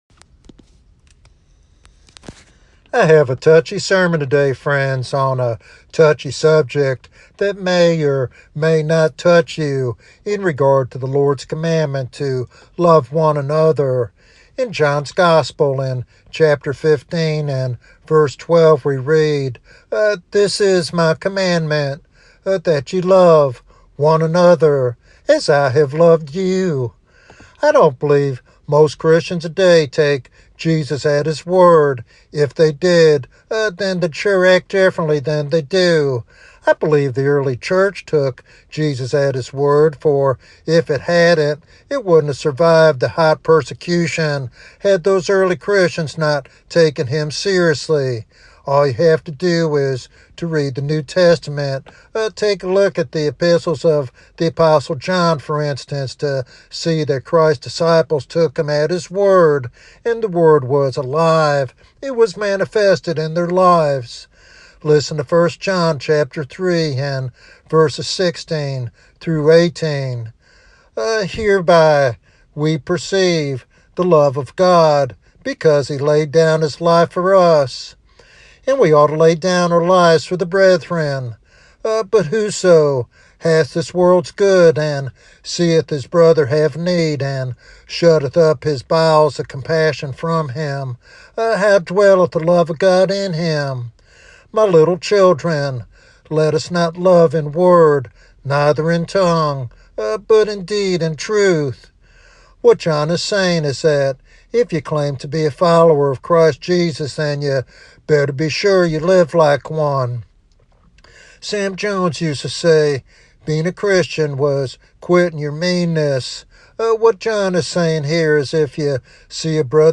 In this challenging sermon